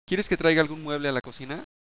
Ejemplo 1: Una elocución etiquetada en los niveles: alófonos, sílabas fonéticas, palabras y break índices (sin reparaciones de habla)